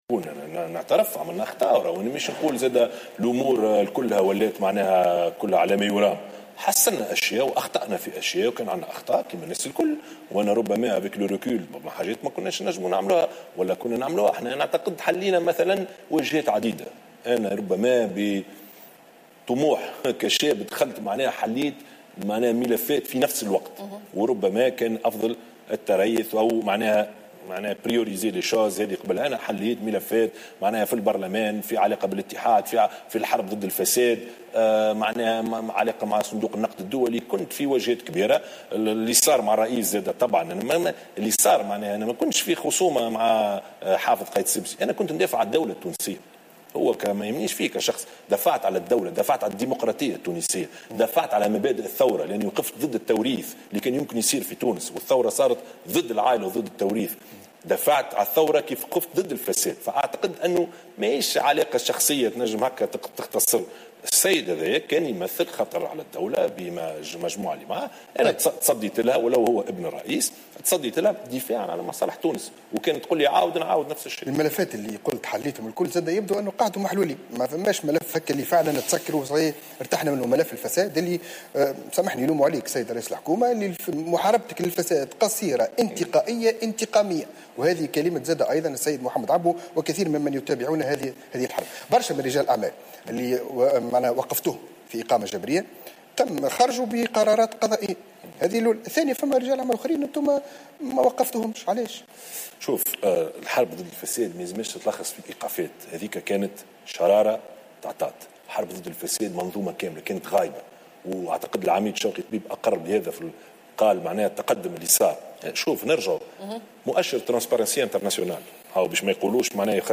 قال رئيس حكومة تصريف الأعمال يوسف الشاهد في حوار على القناة الوطنية الأولى مساء اليوم إن قيس سعيد رجل وطني صادق و جاء فقط بهدف خدمة البلاد.